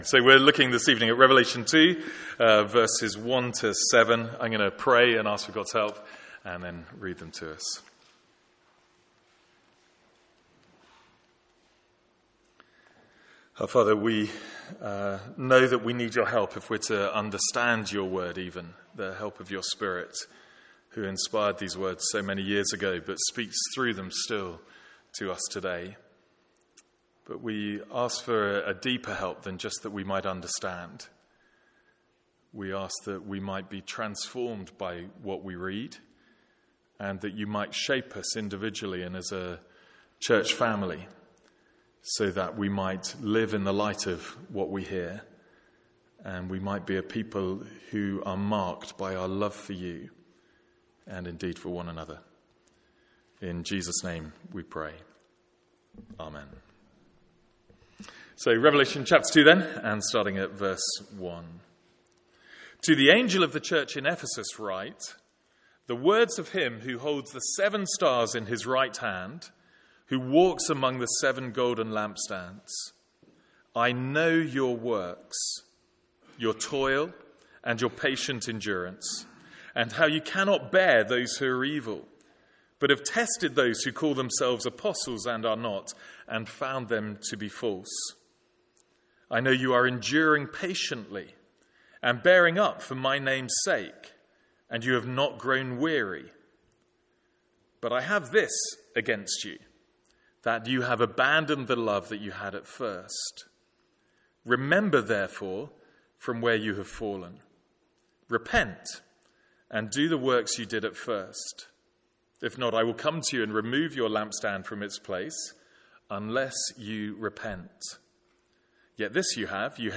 Sermons | St Andrews Free Church
From our evening series in the book of Revelation.